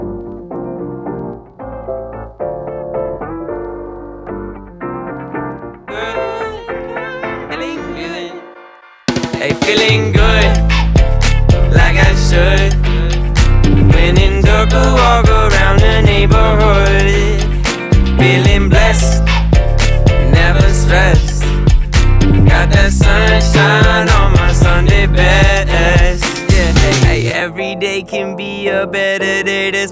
• R&B/Soul
American electro-pop duo